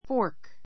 fɔ́ː r k